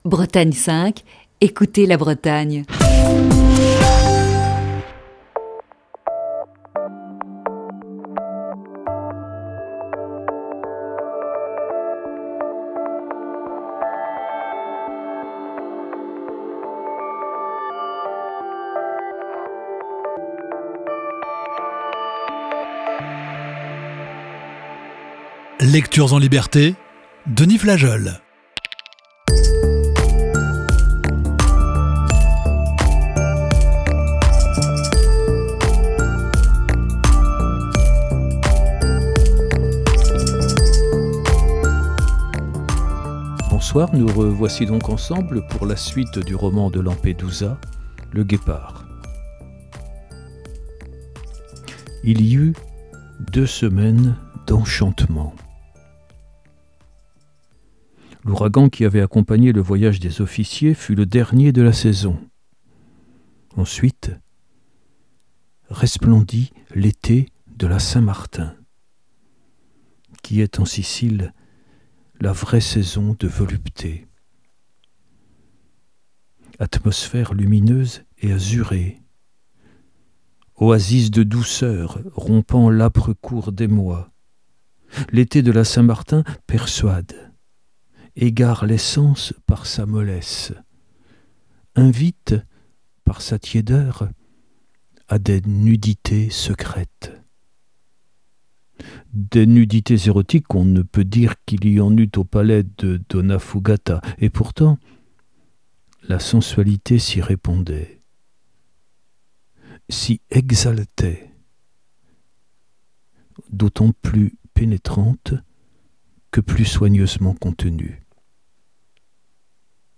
Émission du 3 juin 2020.